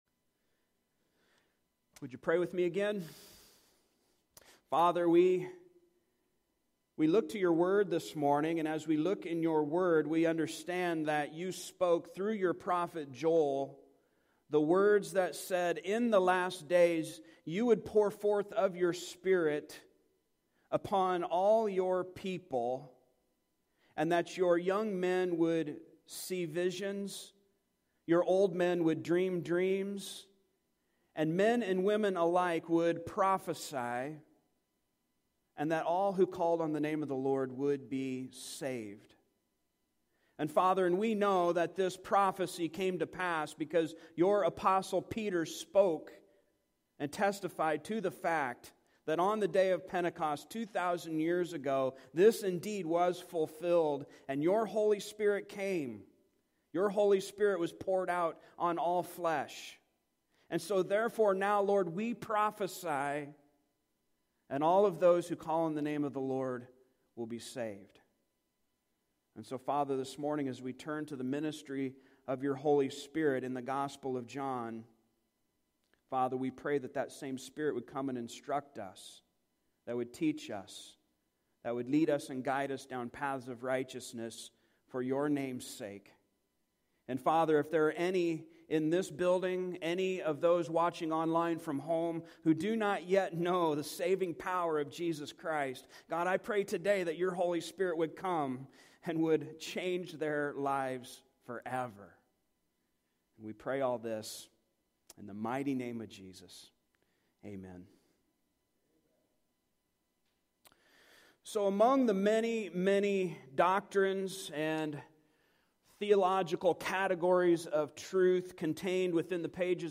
Passage: John 15:26-16:4 Service Type: Sunday Morning